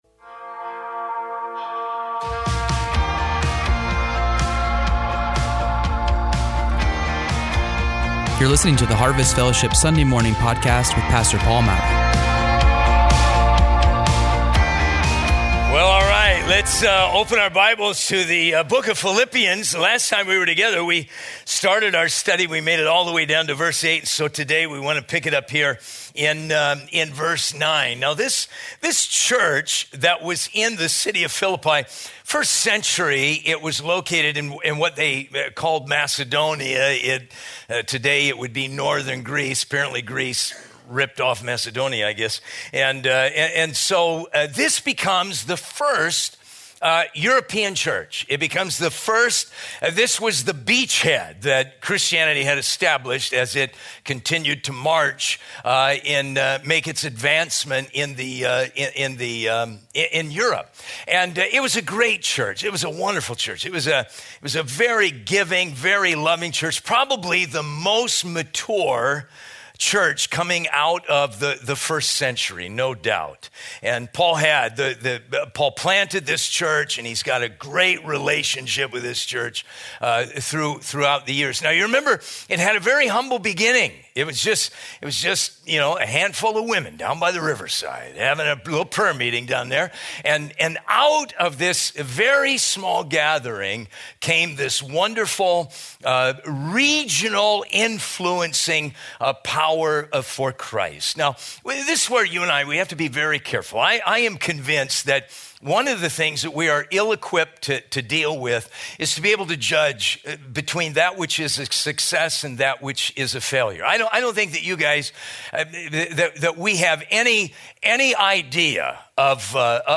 We feature verse by verse teachings through the Bible, topical messages, and updates from the staff and lead team.